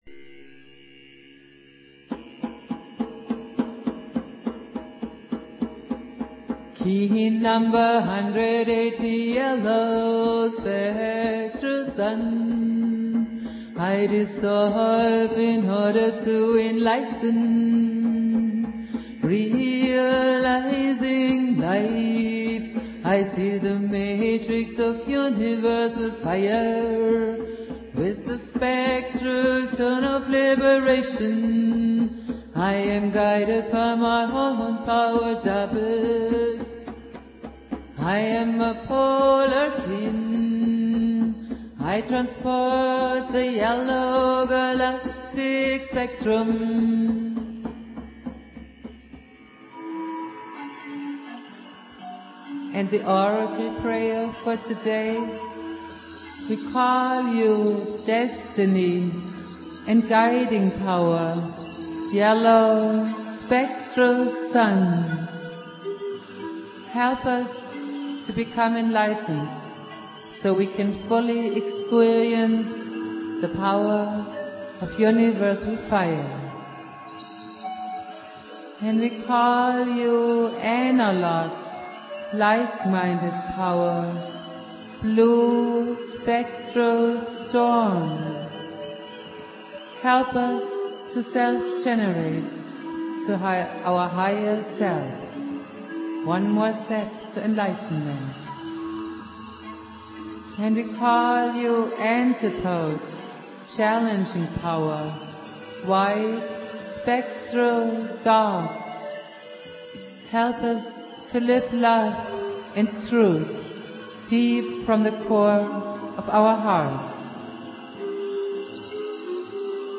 Valum Votan playing flute.
Jose's spirit and teachings go on Jose Argüelles playing flute.